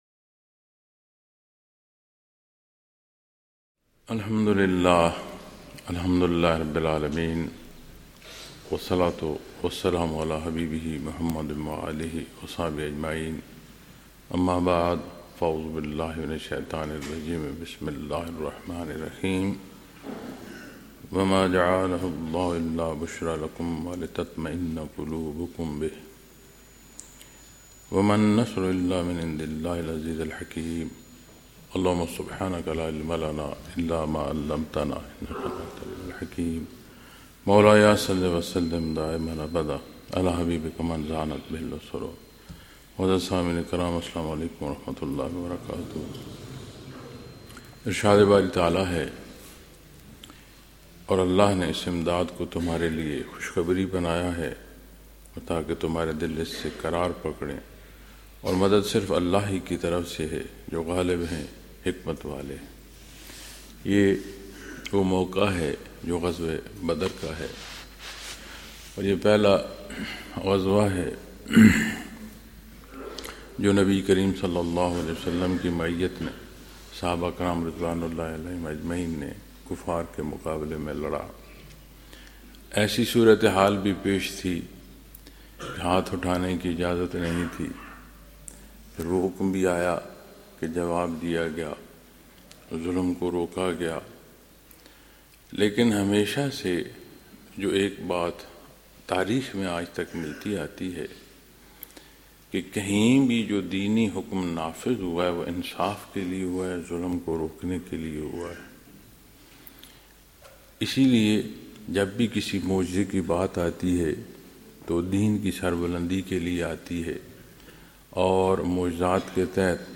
Lectures in Munara, Chakwal, Pakistan on April 5,2026